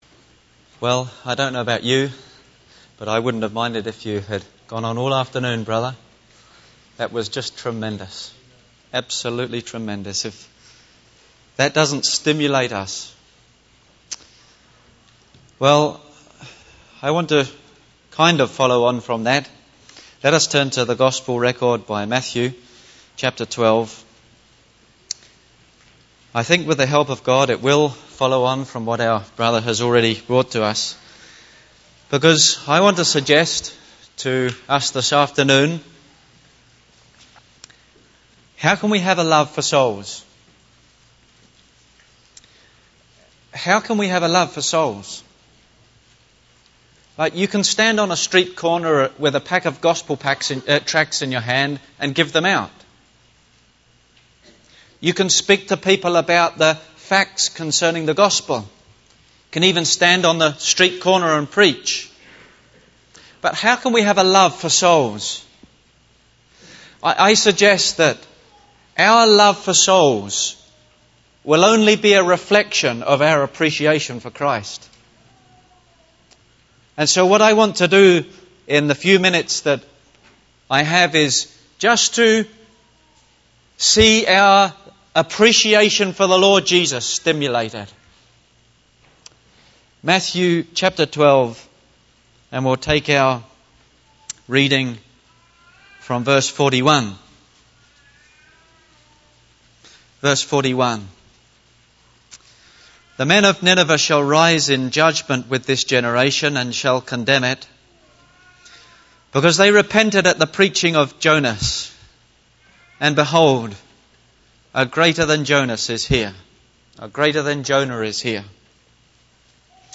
Sarnia Gospel Hall Conference 2015 Service Type: Ministry Topics: Evangelism